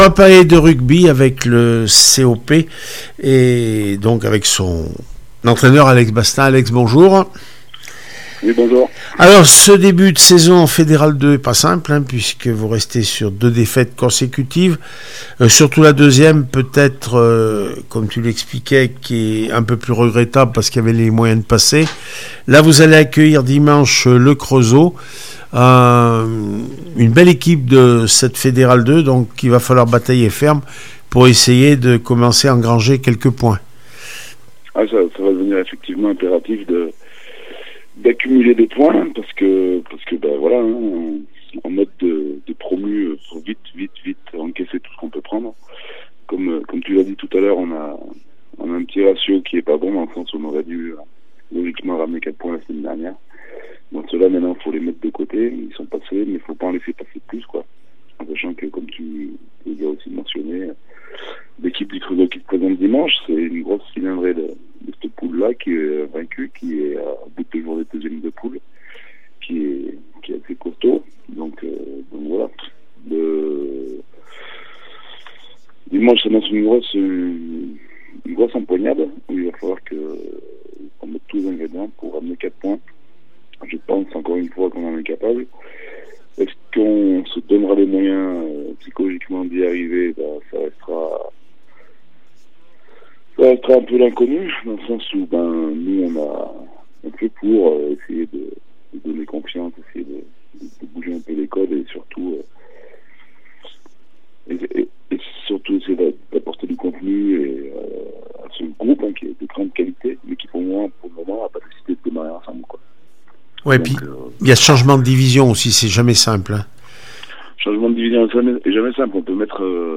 29 septembre 2023   1 - Sport, 1 - Vos interviews